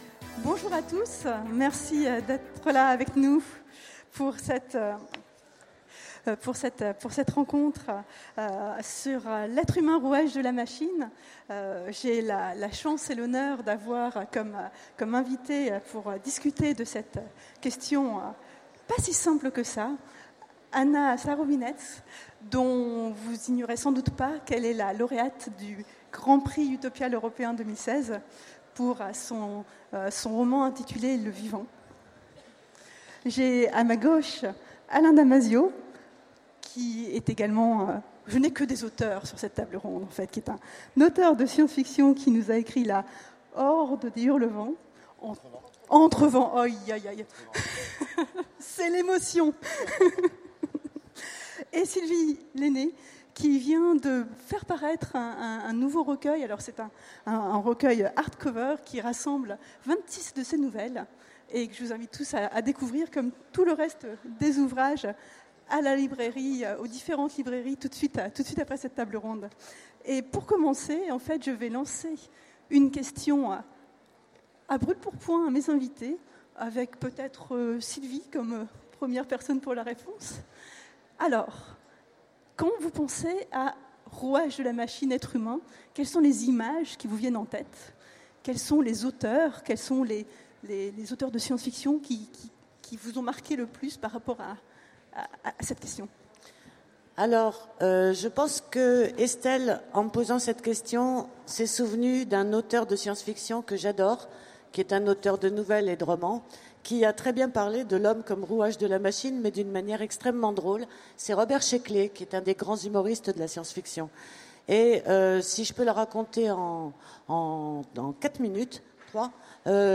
Utopiales 2016 : Conférence L’être humain rouage de la machine